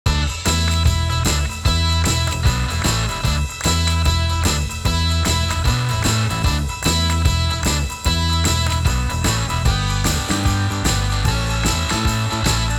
サウンドデモ
ミックス（原音）
SA-3_MixBus_Bypassed.wav